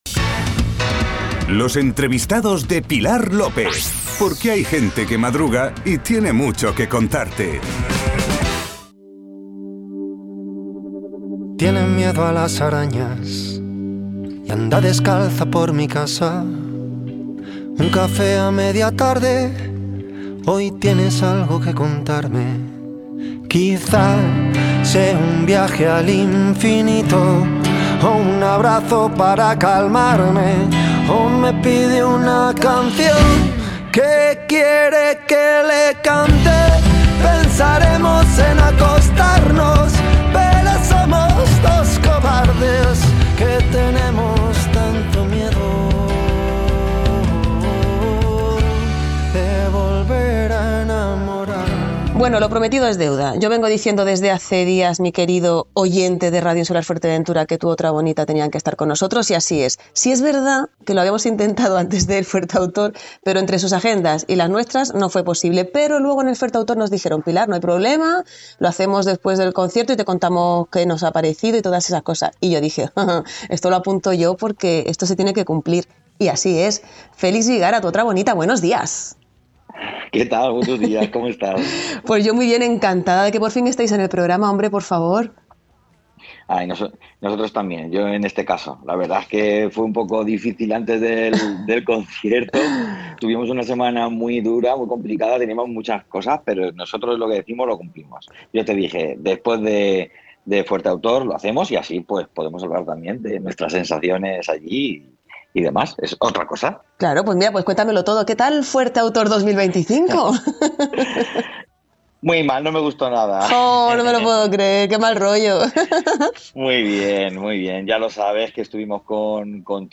Entrevista-en-Suena-Bien-a-Tu-Otra-Bonita.mp3